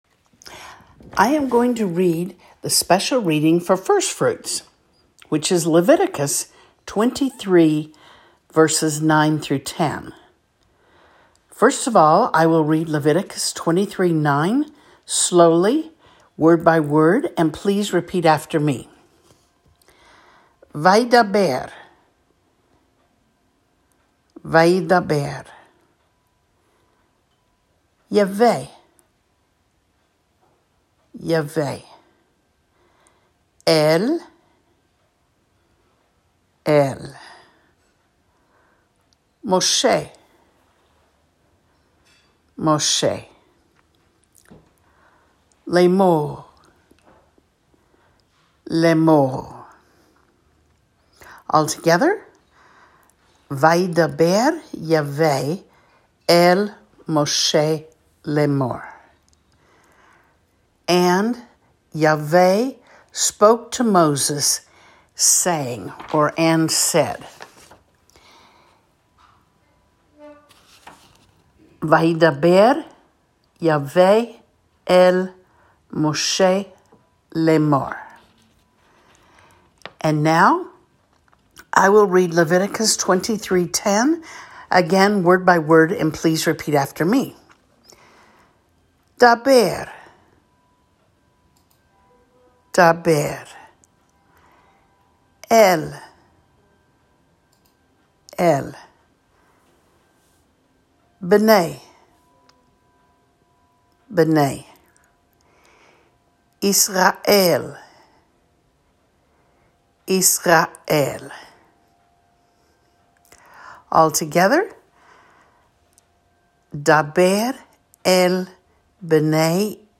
Hebrew & English handouts & classroom Hebrew reading